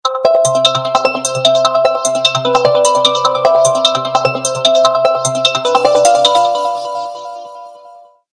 Human Rush-sound-HIingtone
human-rush_25082.mp3